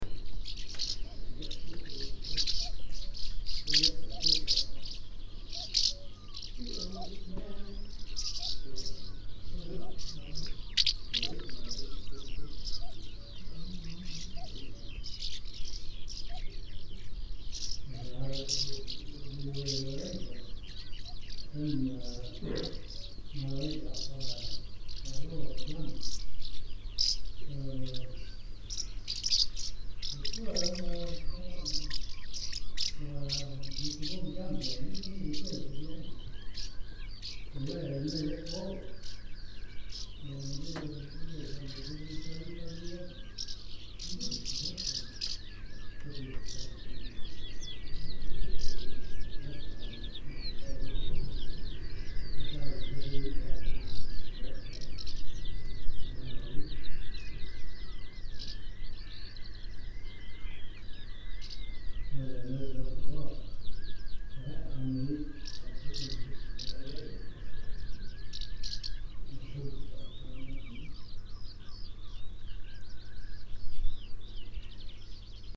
Tipperne, Denmark
PFR01239, 090610, Common House Martin Delichon urbicum, farmers, Tipperne, Denmark